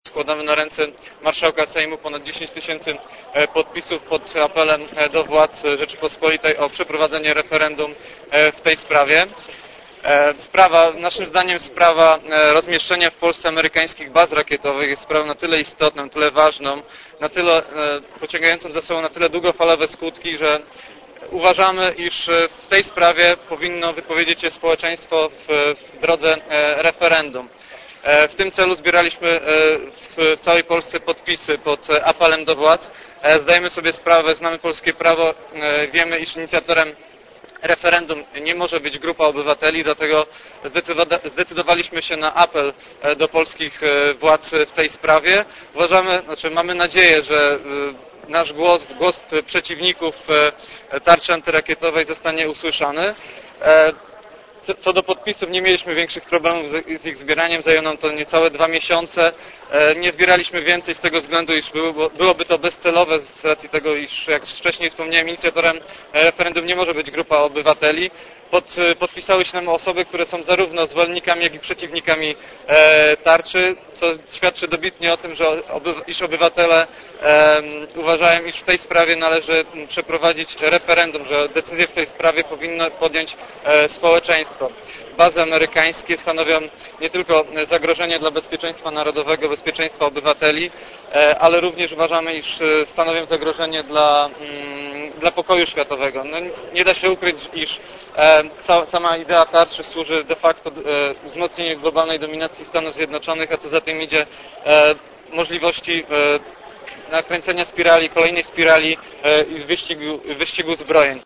Dopiero po blisko godzinie Młodym Socjalistom udało dostać do gmachu Sejmu gdzie zaimprowizowali konferencję prasową.